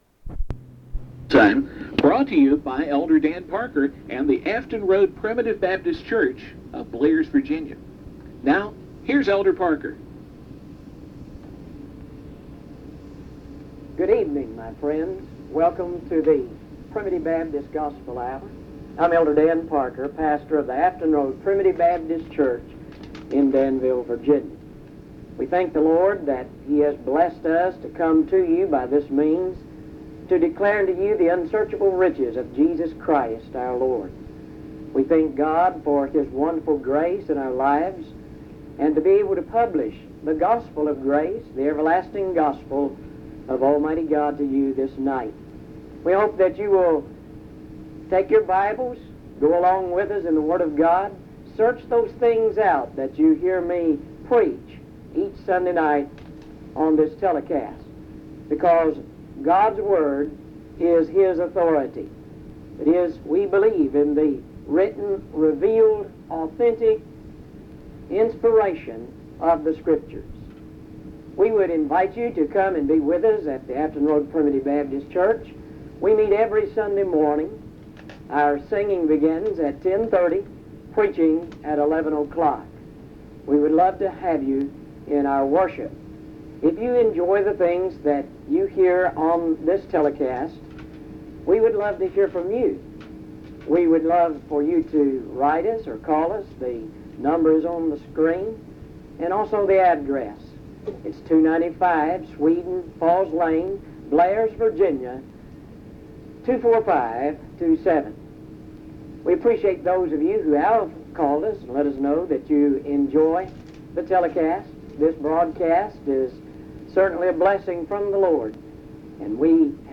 1 Peter 1; Recording from a broadcast